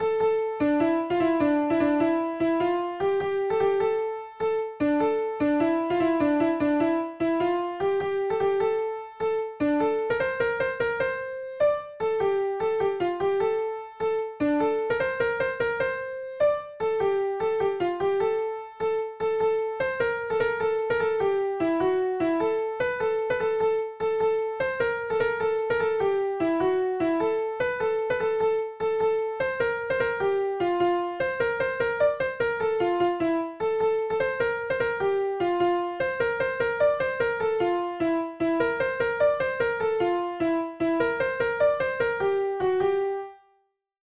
Tenor Part